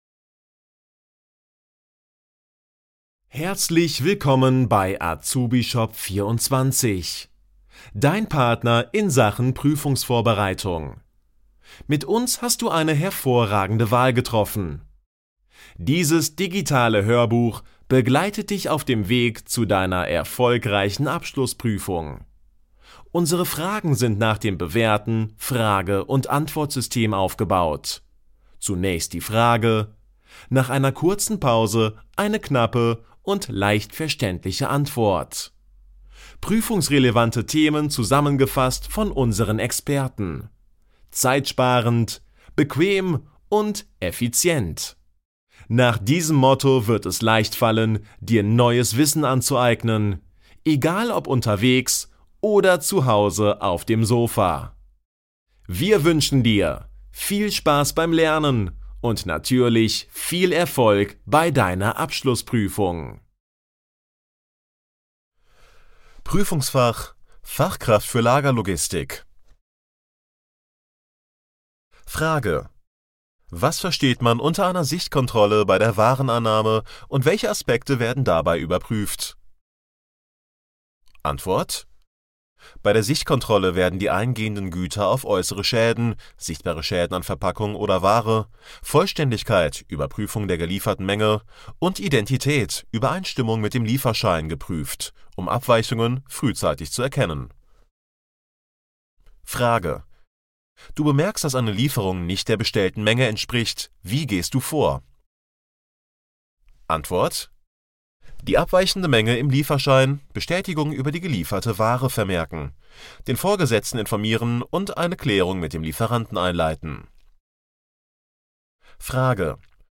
MP3 Hörbuch Fachkraft für Lagerlogistik - Download